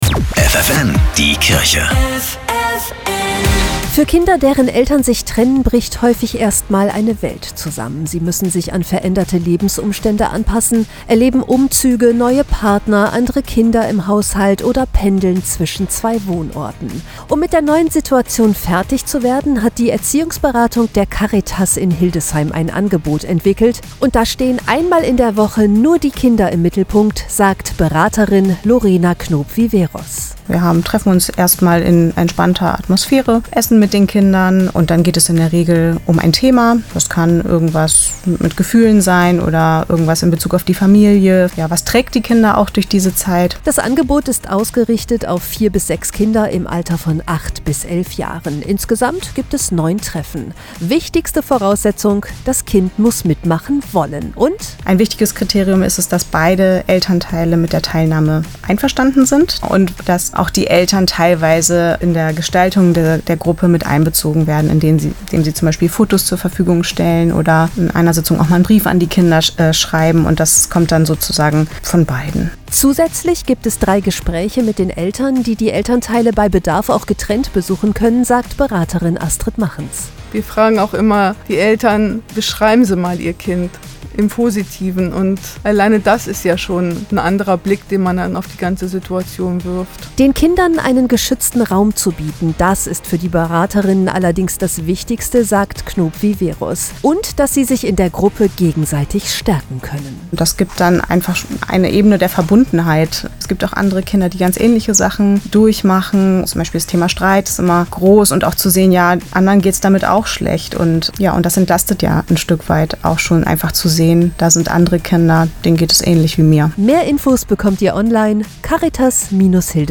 Radiobeiträge: